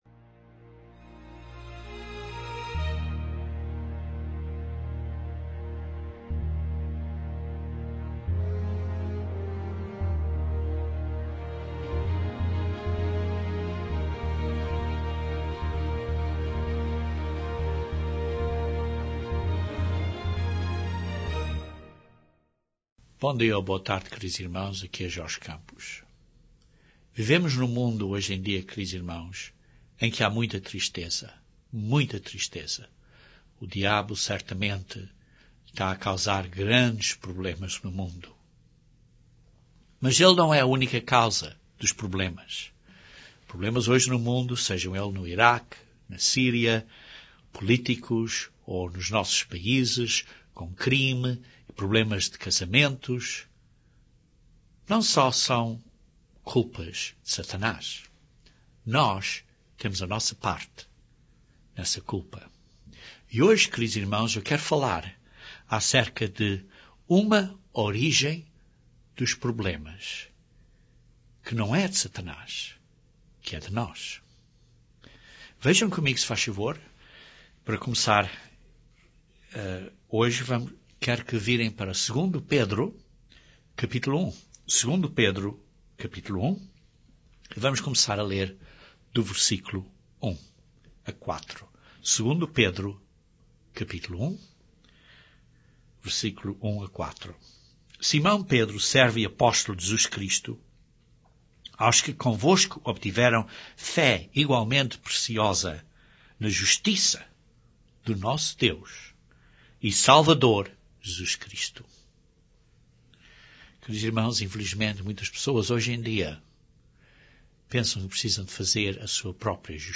O mundo tem muitos problemas. Este sermão descreve uma das causas principais dos nossos problemas mundiais.